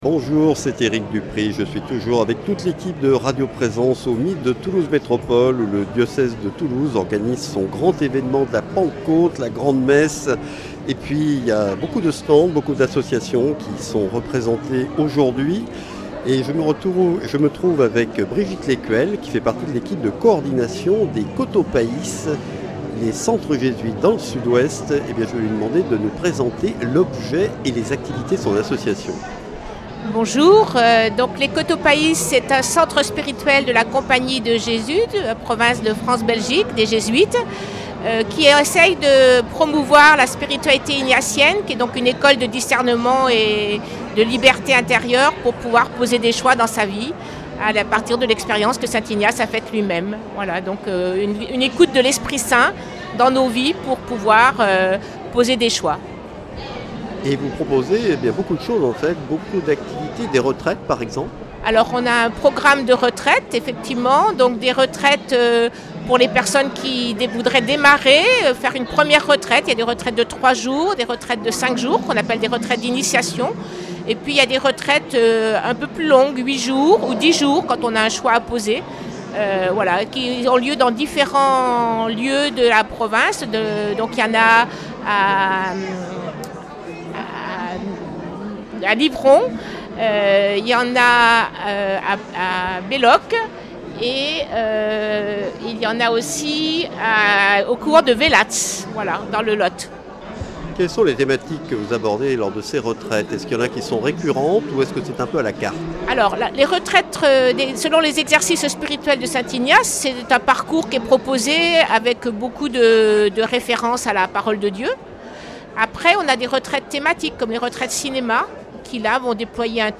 À travers cette interview, on découvre comment la foi, la solidarité et l’ancrage local nourrissent un engagement simple et profond au service des autres. Une parole de terrain, vivante et inspirante, pour préparer les cœurs à la fête de l’Esprit.